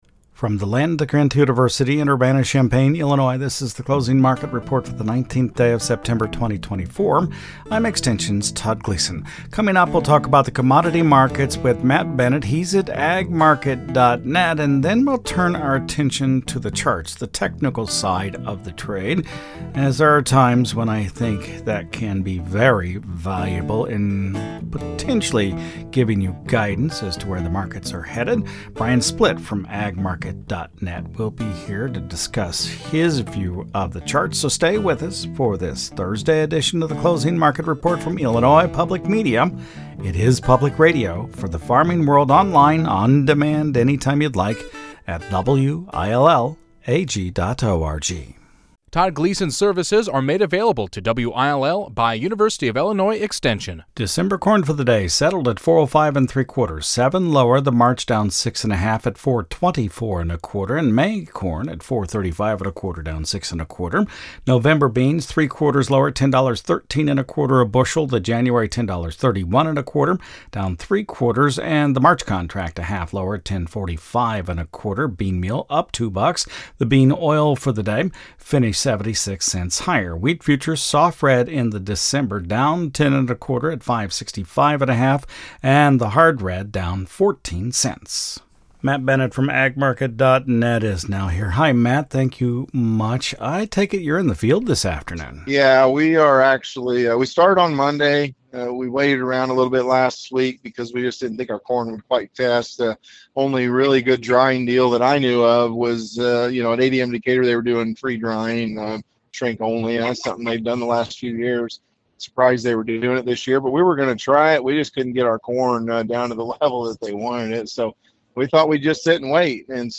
from the combine cab